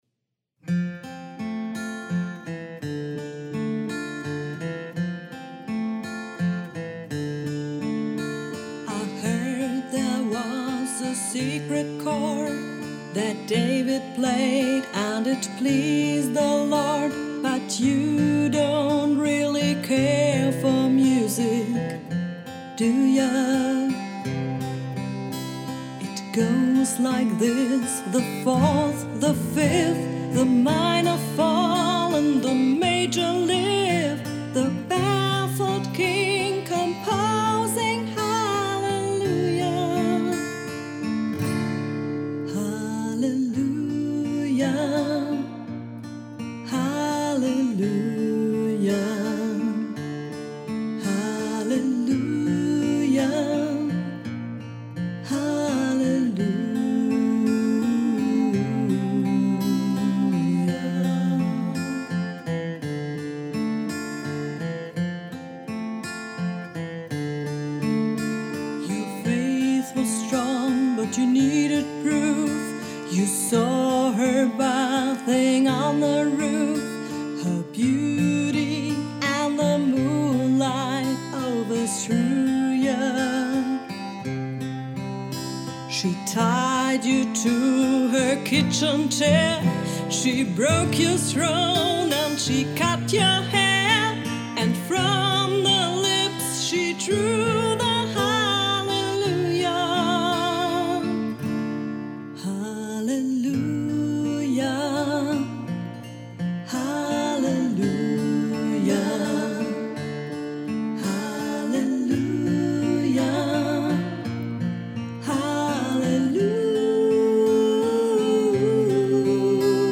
Liveband
fetziger Party-Rock-Musik
• Coverband